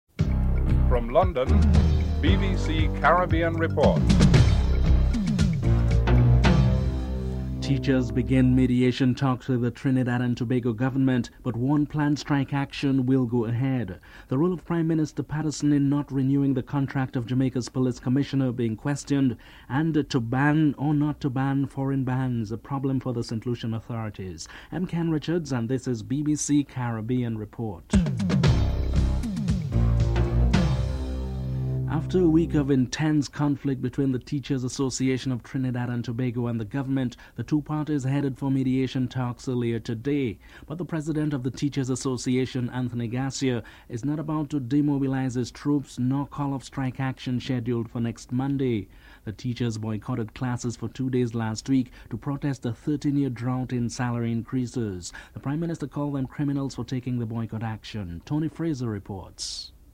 1. Headlines (00:00-00:30)
Opposition Leader Baldwin Spencer is interviewed (06:29-09:37)